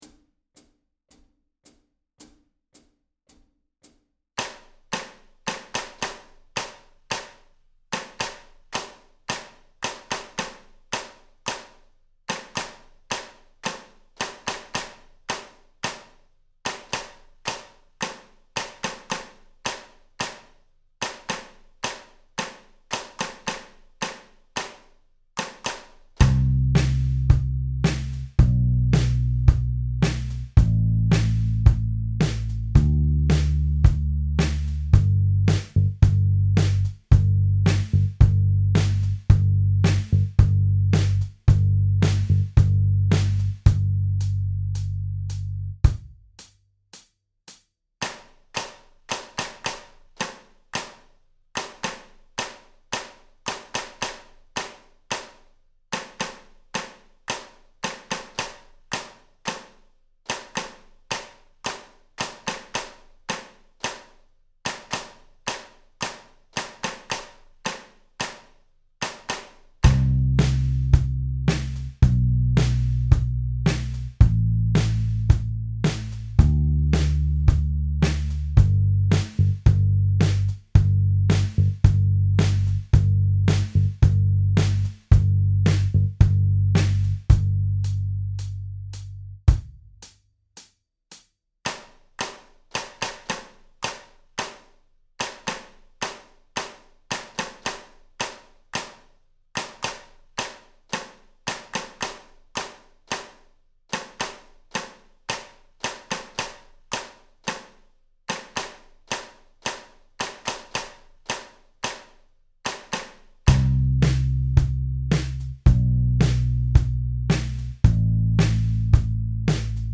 Audio Practice Tracks
Each practice track has a 2 measure count-in, and then the clapping percussion begins.
Slowest Tempo (110bpm) - download, or press the play button below to stream: